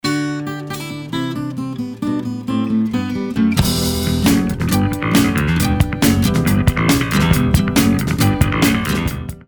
гитара
без слов
Отличный интригующий рингтон на смс.